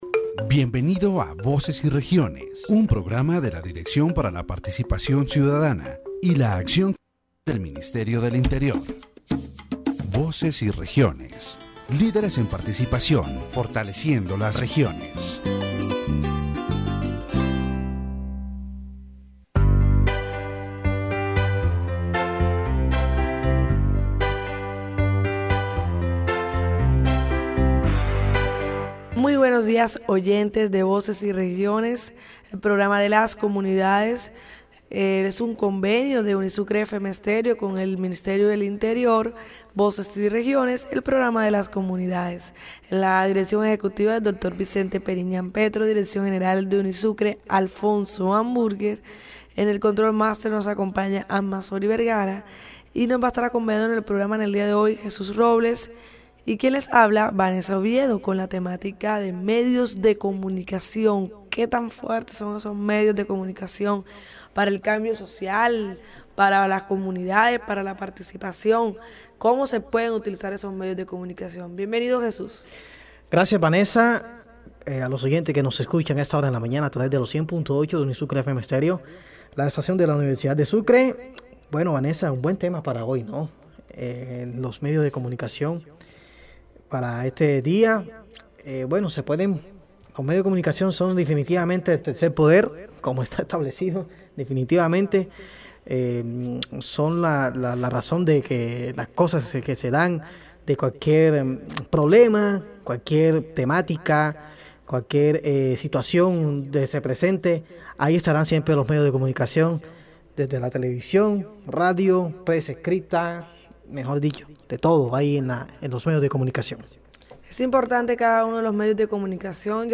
The interview highlights the importance of the media as fundamental tools for the democratization of information, the promotion of citizen participation and the strengthening of communities.